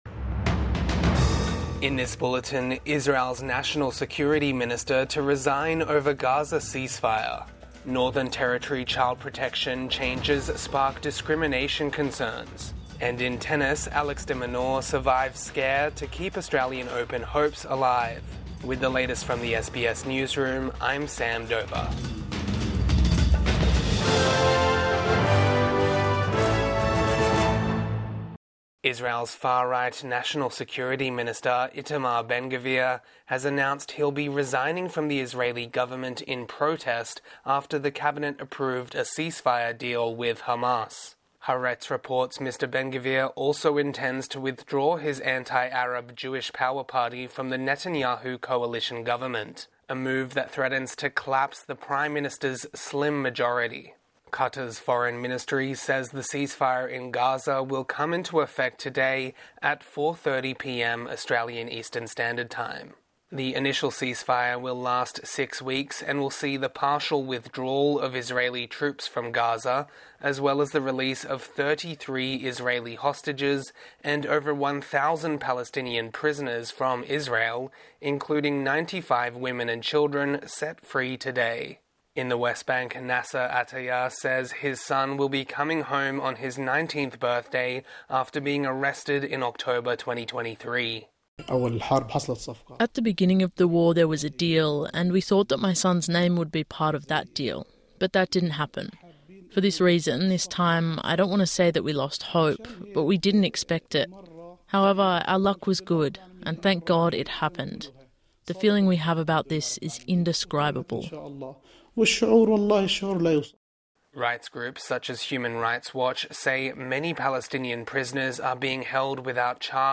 Morning News Bulletin 19 January 2025